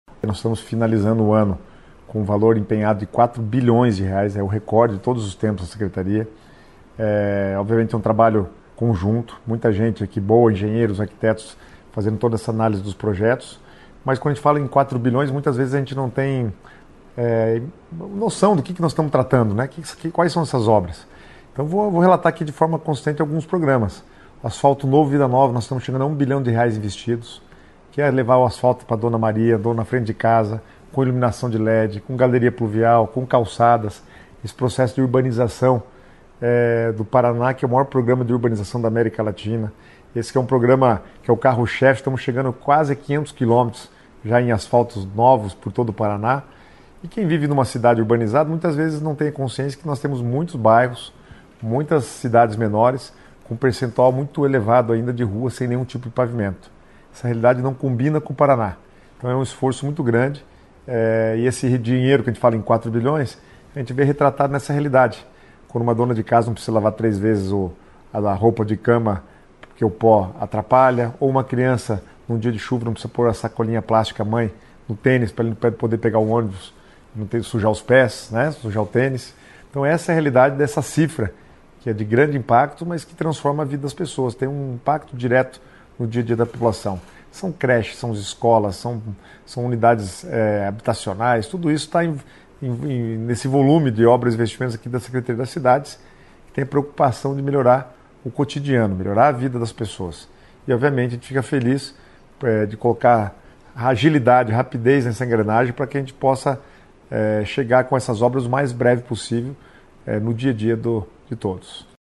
Sonora do secretário Estadual das Cidades, Guto Silva, sobre as grandes obras no Paraná em 2025